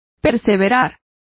Complete with pronunciation of the translation of persevere.